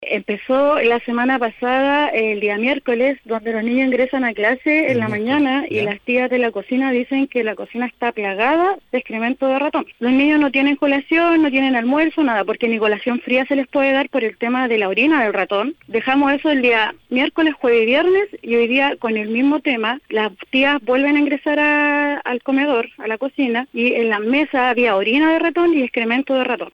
Una apoderada del establecimiento detalló que las mismas manipuladoras de alimentos afirmaron que la cocina está plagada de roedores.